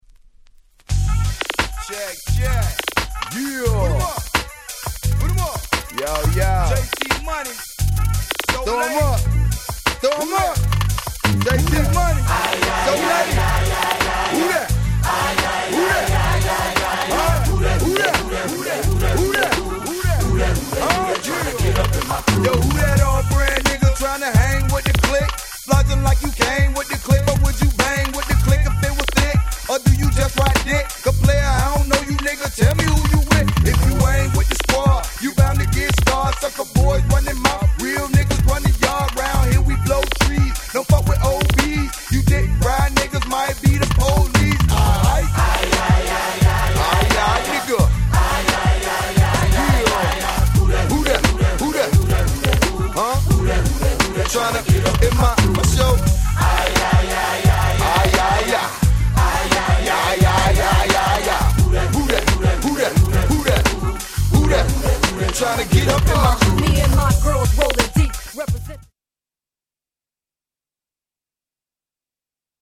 Southern Hip Hop Classic !!